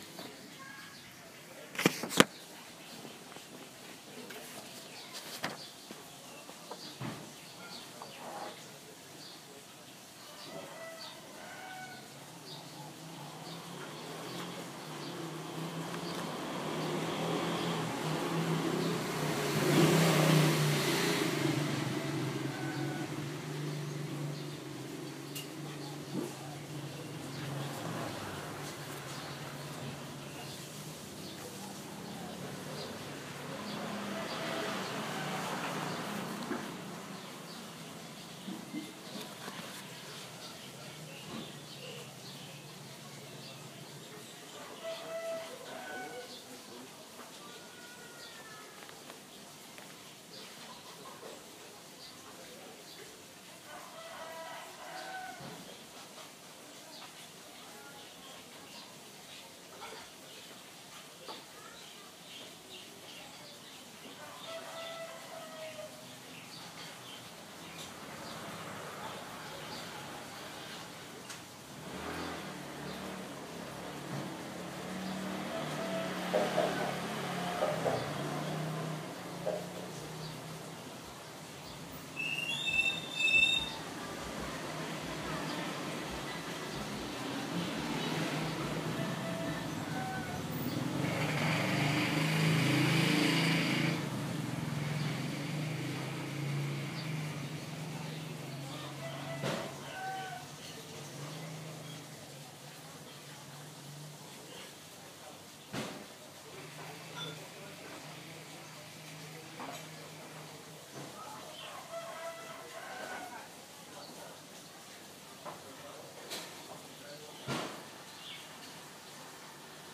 작지만 나를 안아주고 있다는 아늑한 느낌이 충분한 정원에는 작은 물길이 흐른다.